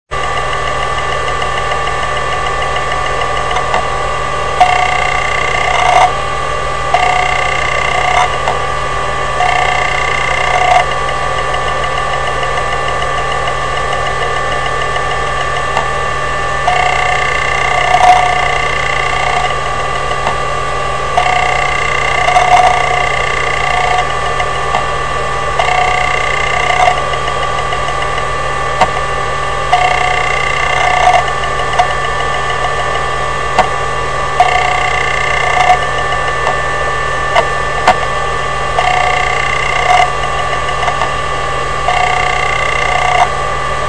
普通のアクセスエラーの音
この音は全面検査中に取った音です。いわゆる「カツッ、カラカラカラ」を繰り返す タイプです。だだ、途中「カラカラ」という音の中に濁った音が混じるのが特徴です。